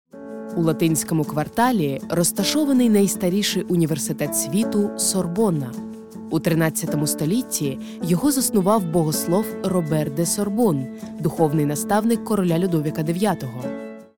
Deep, Natural, Versatile
Audio guide